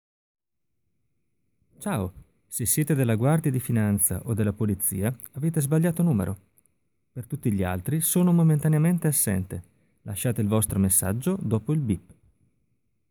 here is my original TWP VoiceMail message, as it was directly recorded from my phone, without ANY post-editing.
As you can see by hearing the unedited linked file above, there are no hiss, pops, cracks, even on the prounonced "P"s.
I do hear some hissing in your message, but it might be your accent :stuck_out_tongue:
It’s not terrible but it still sounds like recorded through a phone.
Thimbleweed_Park_Voicemail.m4a